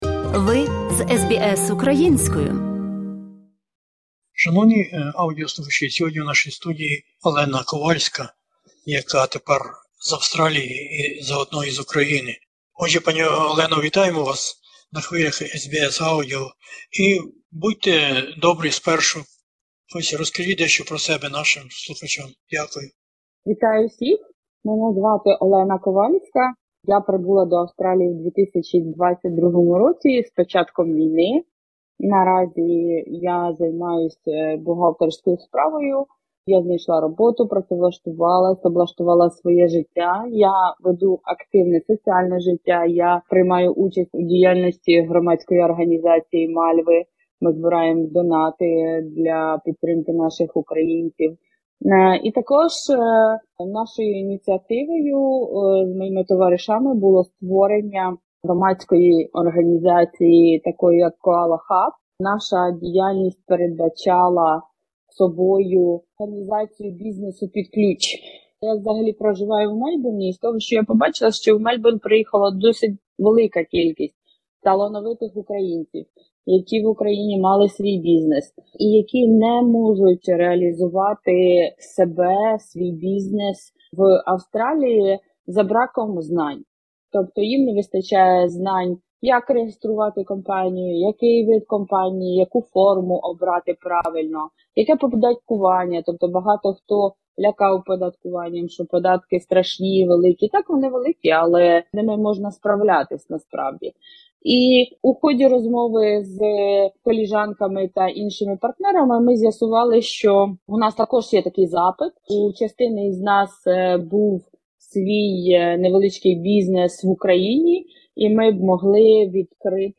З початку року 2022-го до Австралії прибуло багато новоприбулих українців, котрих змусила до неперeдбачуваної та вимушеної міґрації війна на українських землях, що розпочлася у 2014-му році, але особливо великого масштабу набула уже понад 3 роки тому. Тут - розмова SBS Ukrainian із одною новоприбулою